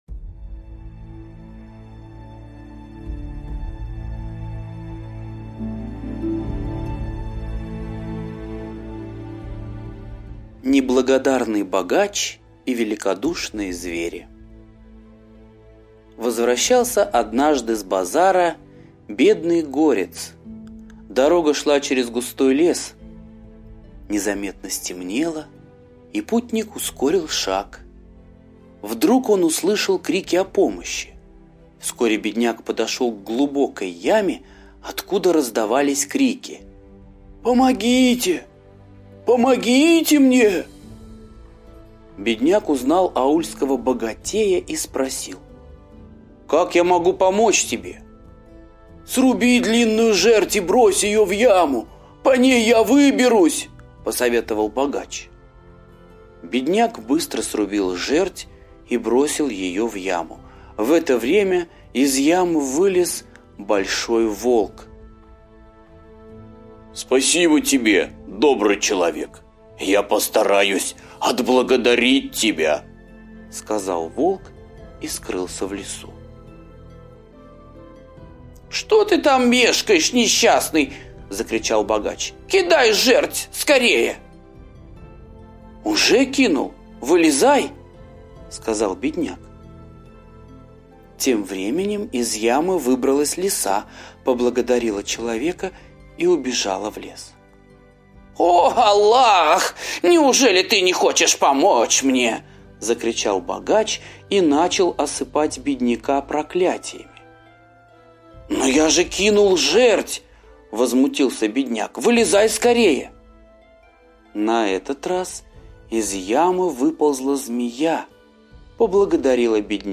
Неблагодарный богач и великодушные звери – арабская аудиосказка